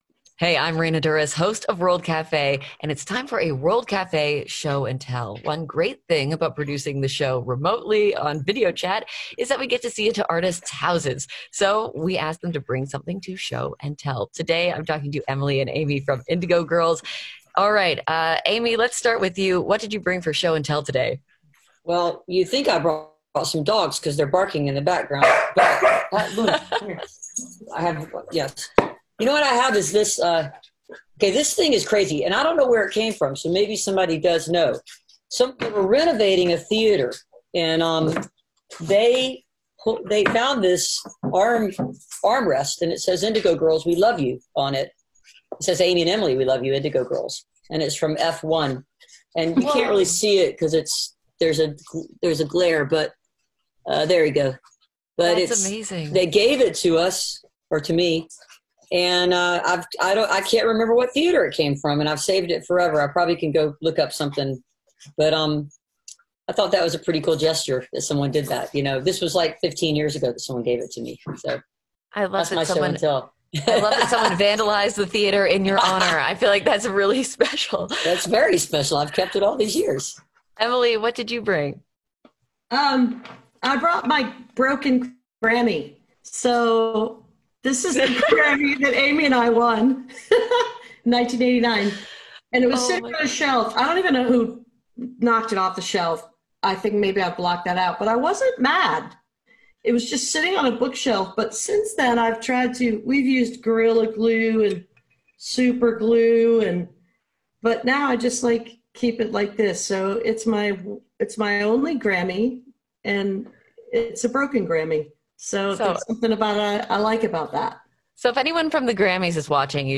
(captured from a facebook live stream)